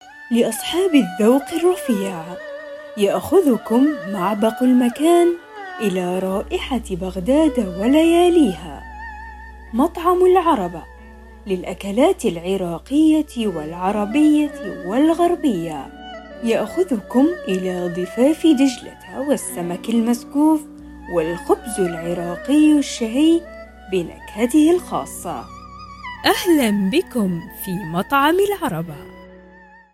品牌广告【温柔动听】